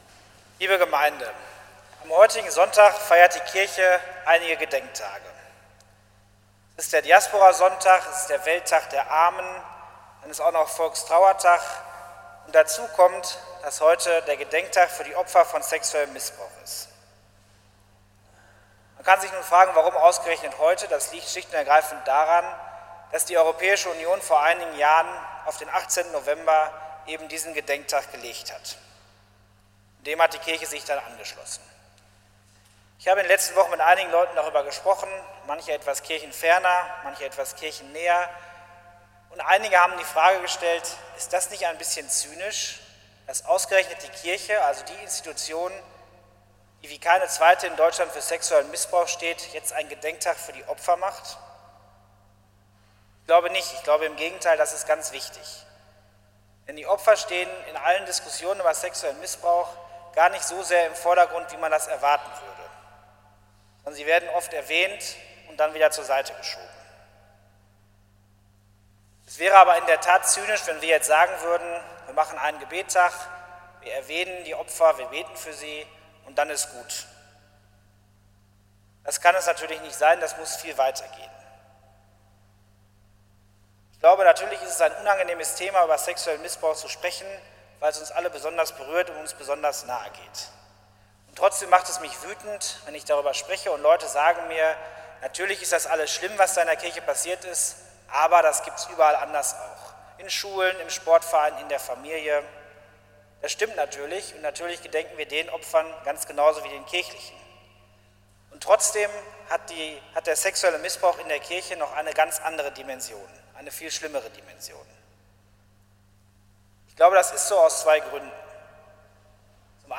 Predigt zum 33. Sonntag im Jahreskreis 2018 (B)